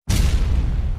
Ogre,Troll,Dino Die.mp3